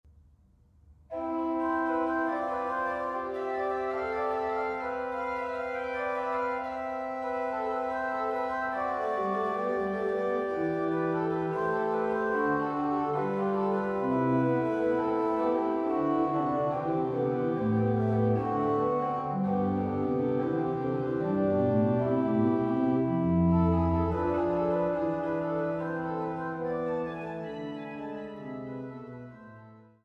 Fuge B-Dur